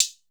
TS Hat 2.wav